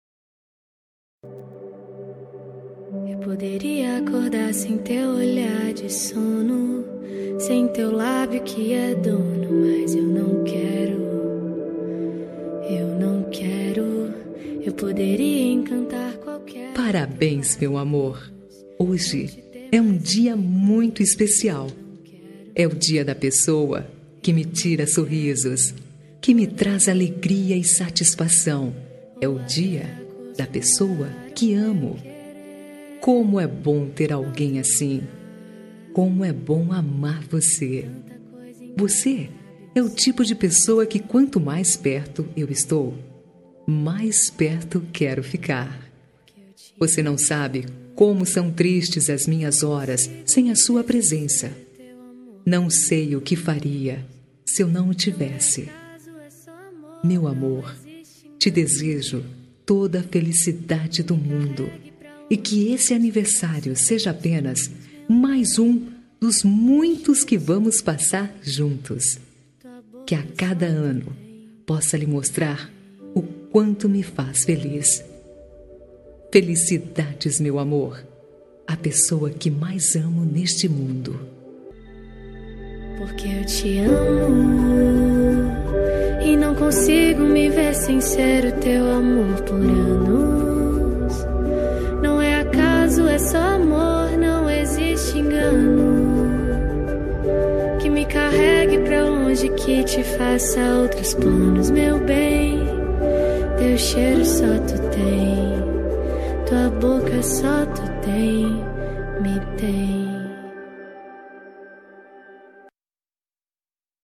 Telemensagem de Aniversário Romântico – Voz Feminina – Cód: 202111 – Linda